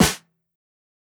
SNARE_DEATH.wav